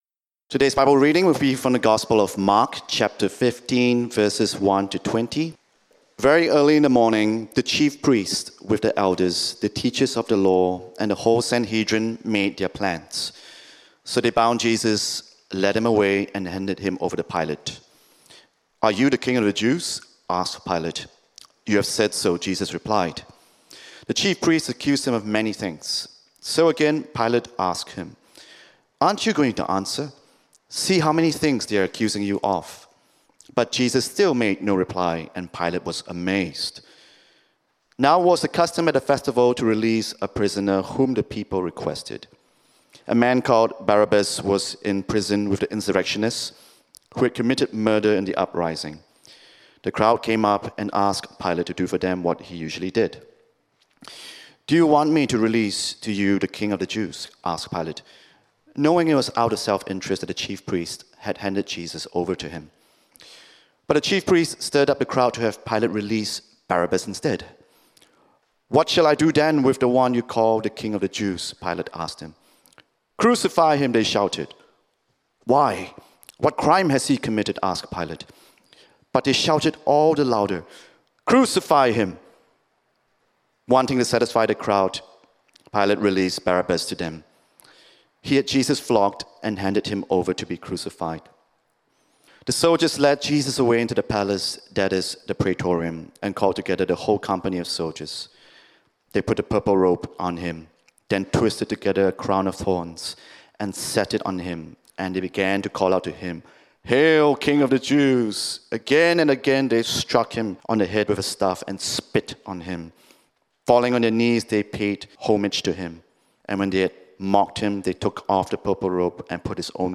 Good Friday & Easter 2025